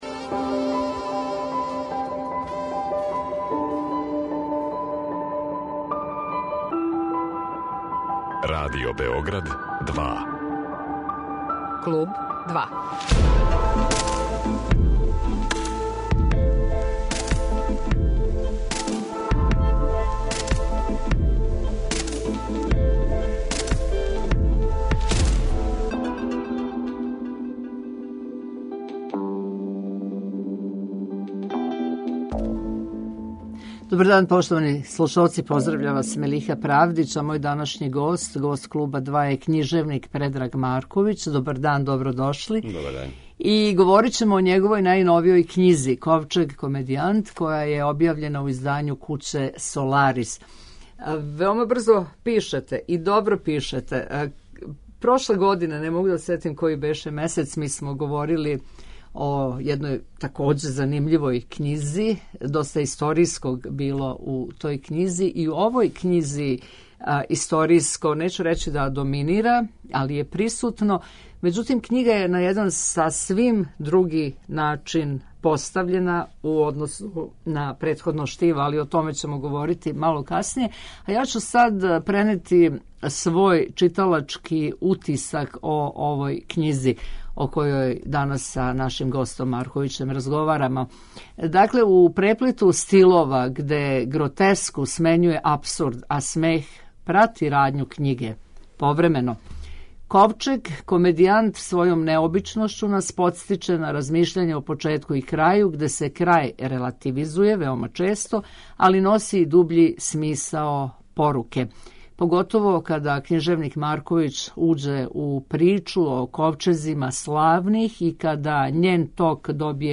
Предраг Марковић, гост емисије